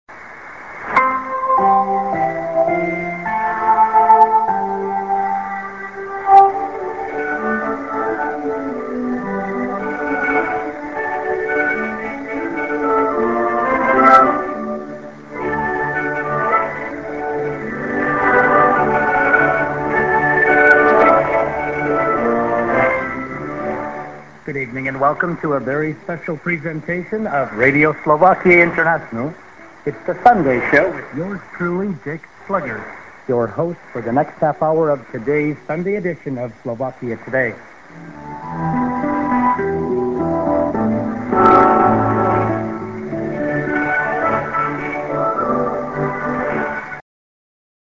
b:　IS->music->ID(man)->